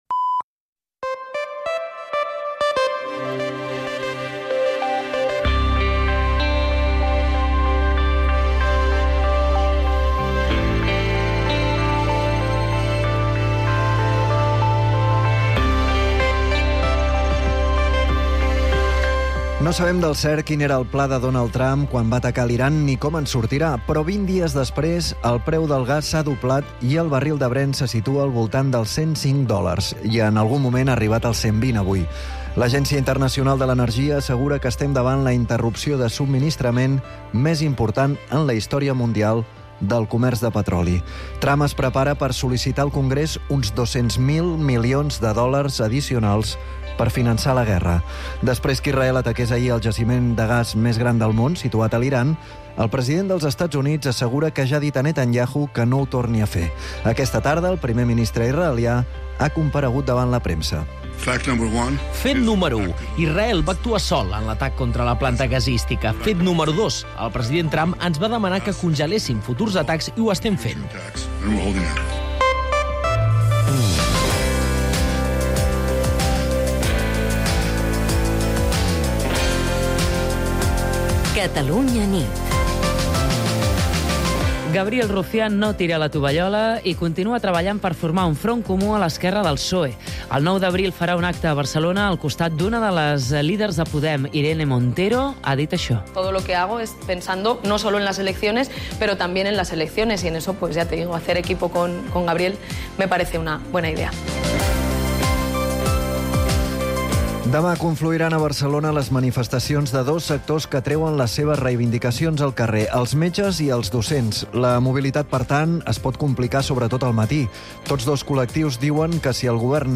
l’informatiu nocturn de Catalunya Ràdio